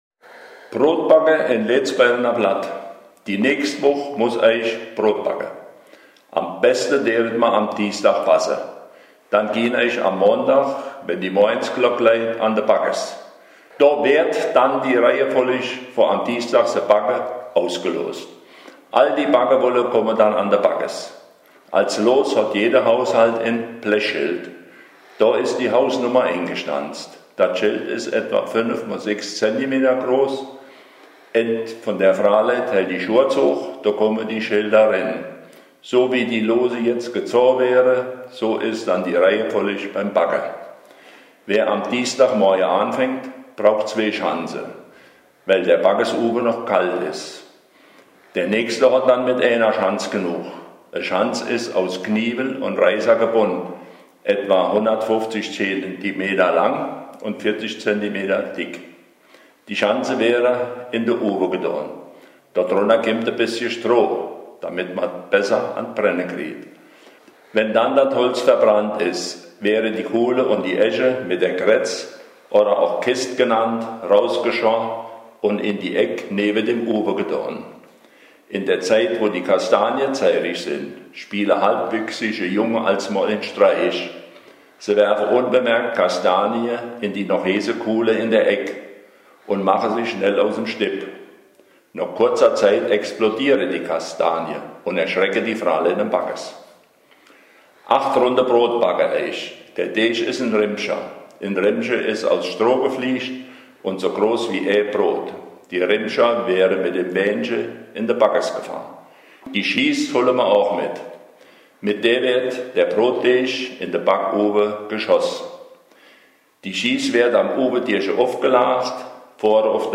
Zum Mit- oder Nachlesen: Brot bagge in Lötzbeure in Letzbeirena Platt [pdf]